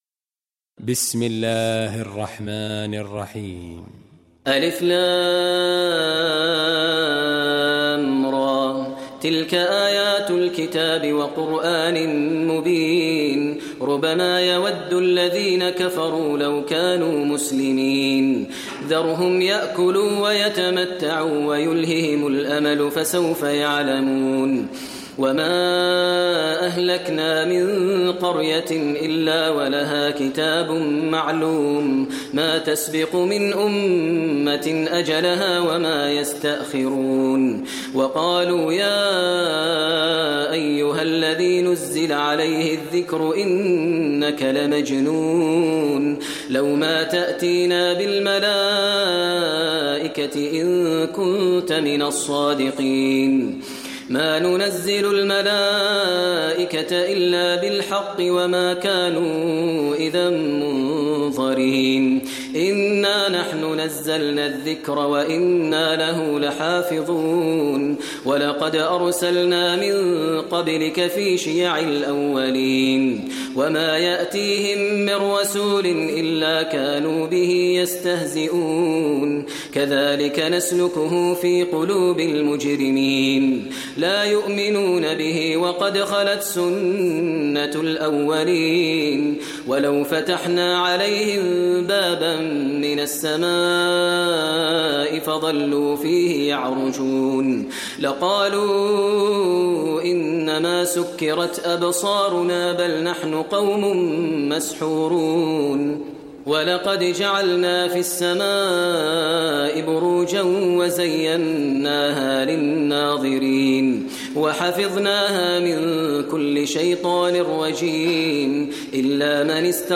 Surah Al Hijr Recitation by Maher al Mueaqly
Surah Al Hijr, listen online mp3 tilawat / recitation in Arabic recited by Imam e Kaaba Sheikh Maher al Mueaqly.